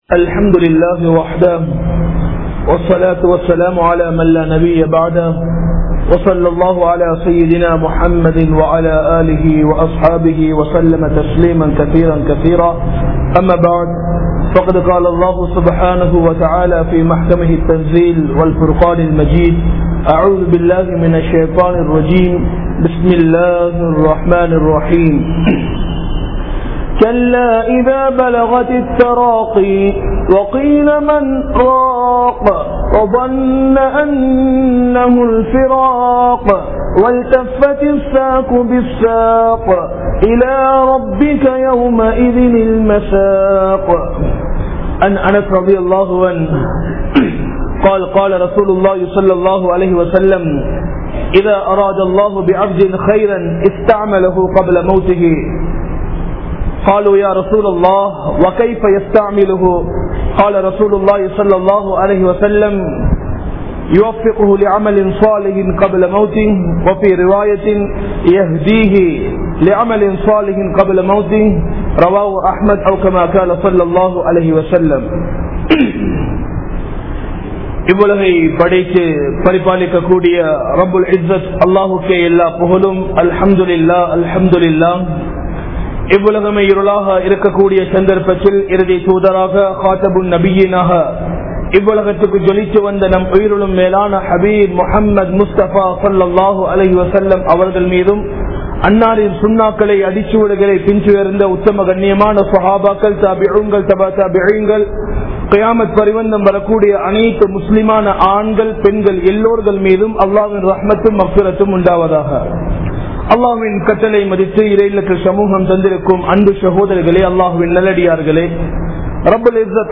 Sakrathudaiya Veathanai (ஸக்ராதுடைய வேதனை) | Audio Bayans | All Ceylon Muslim Youth Community | Addalaichenai
Muhiyadeen Jumua Masjith